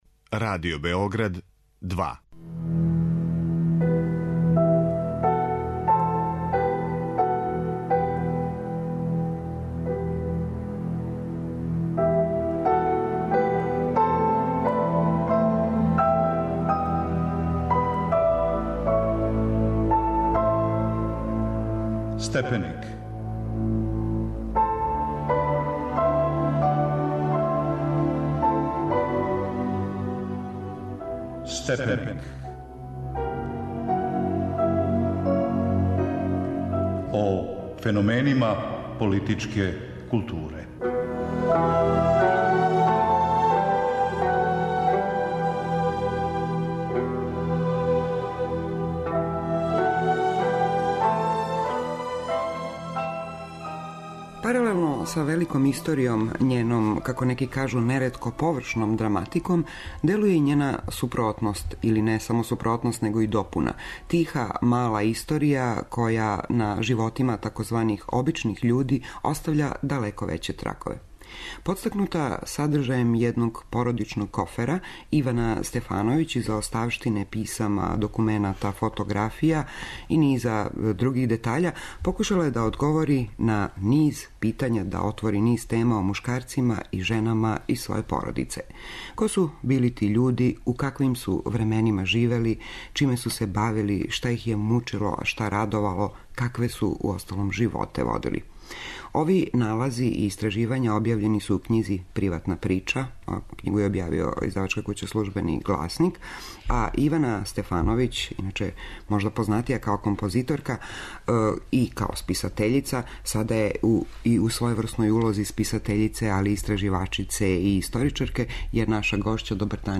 разговара